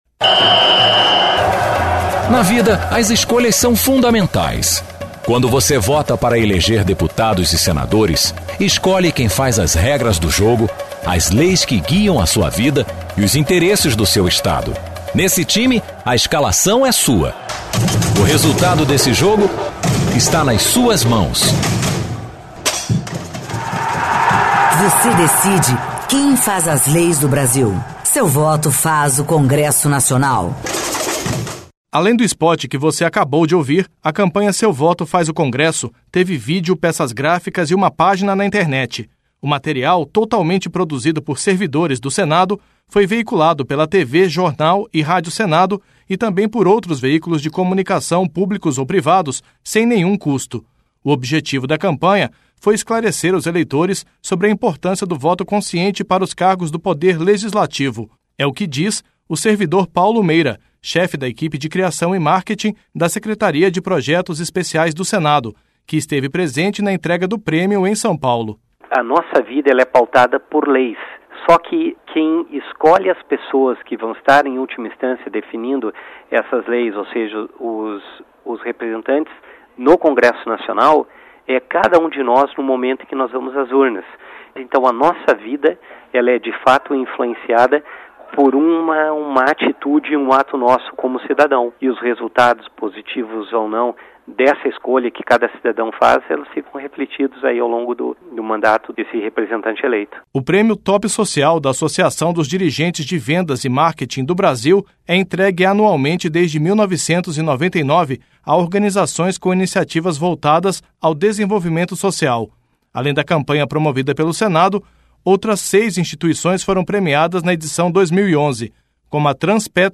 Além do spot que você acabou de ouvir, a campanha Seu Voto faz o Congresso teve vídeo, peças gráficas e uma página na internet.